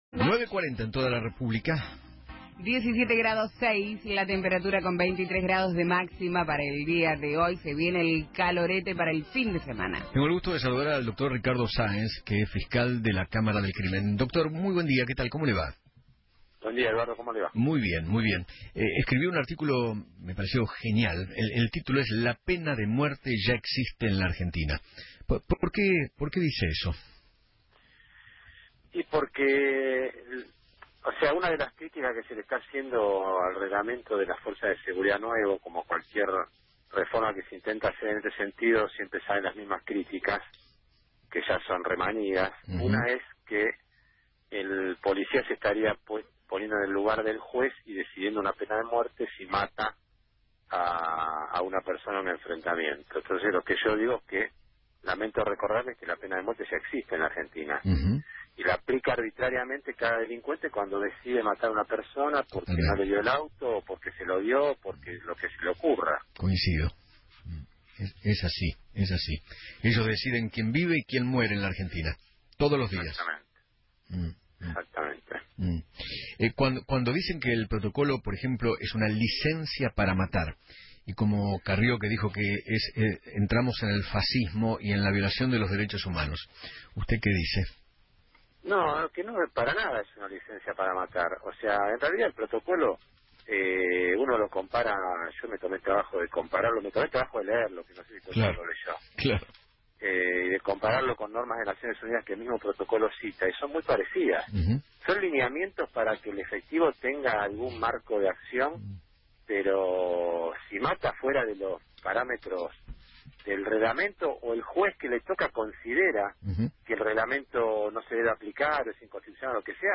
El fiscal general Ricardo Saenz habló en Feinmann 910 y dijo que “Una de las criticas que se hace al reglamento de las fuerzas de seguridad nuevo, como cualquier reforma, siempre salen las mismas criticas, primero que el policía se pone en el lugar del Juez y decide una pena de muerte si mata a un delincuente. Lamento decir que la pena de muerte existe en la Argentina y la aplican los delincuentes”